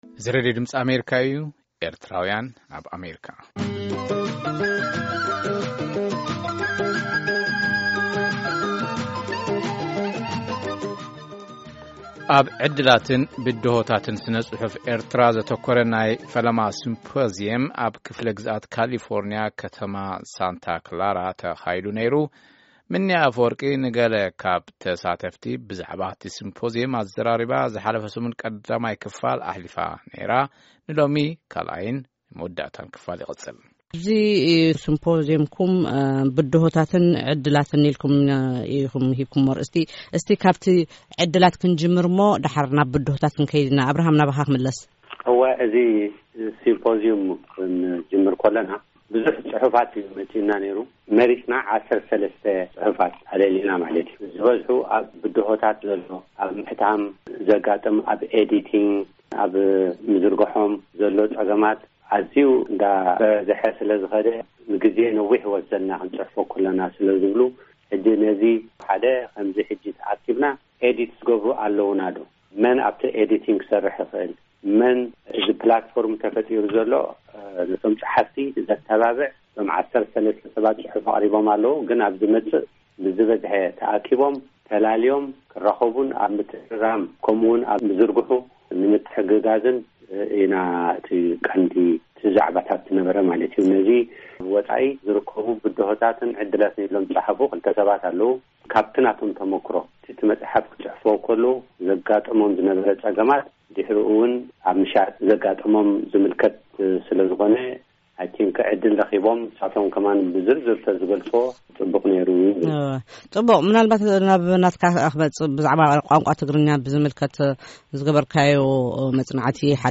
ኣብ ዕድላትን ብድሆታትን ስነ ጽሑፍ ኤርትራ ዘተኮረ ናይ ፈለማ ሲምፖዝዮም ተኻይዱ። እቲ ሲምፖዝዮም ኣብ ክፍለ ግዝኣት ካሊፎንያ ከተማ ሳንታ ክላራ ተኻይዱ። ንገለ ካብ ተሳተፍቲ ብዛዕባ’ቲ ሲምፖዝዮም ኣዘራሪብና ኣለና ምሉእ ትሕዝቶ ኣብዚ ምስማዕ ይክኣል። ኣብ ዕድላትን ብድሆታትን ስነ ጽሑፍ ኤርትራ ዘተኮረ ሲምፖዝዮም ተኻይዱ(2ይ ክፋል)